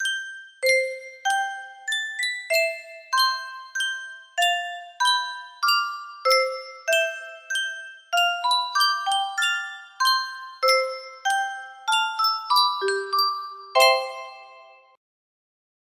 Yunsheng Spieluhr - Viel Glück und viel Segen 3510 music box melody
Full range 60